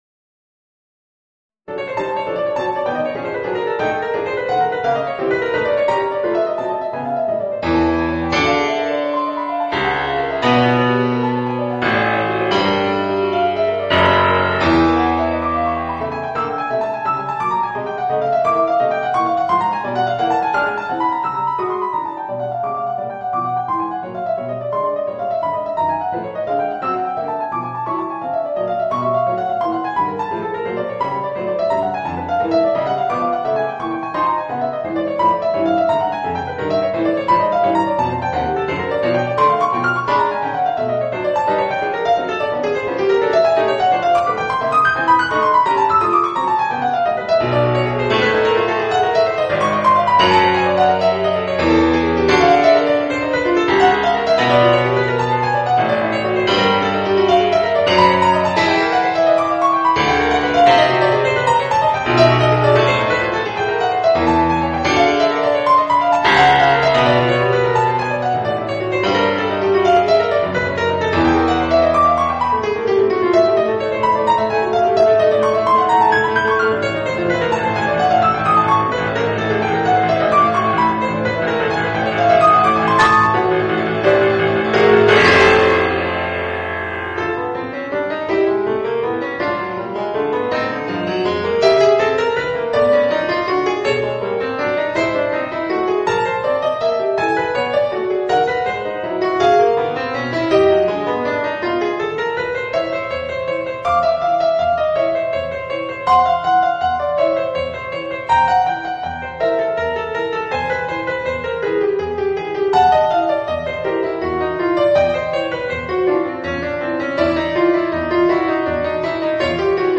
Voicing: Piano Solo